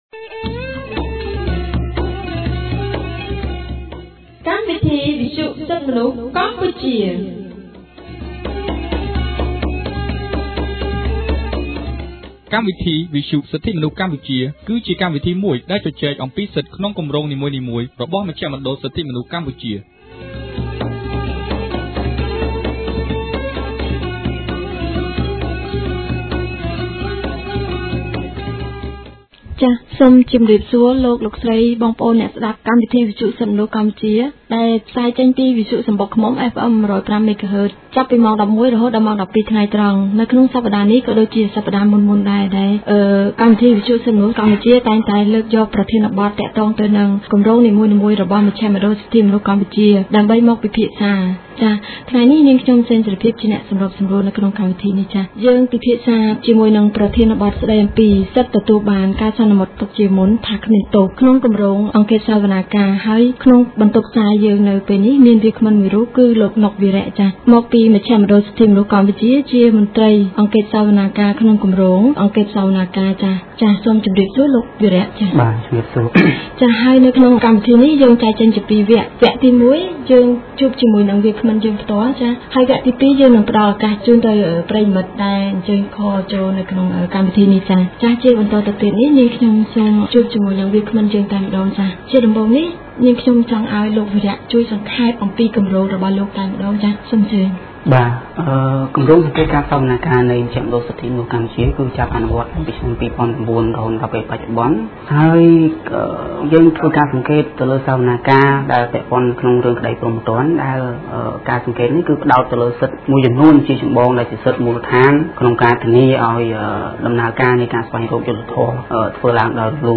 This radio talk show debates on the right of every accused to be presumed innocence until his or her guilty was found in compliance with the law and by an impartial and competent court. This right is recognized by both the Cambodian and international laws and also is a right to a fair trial.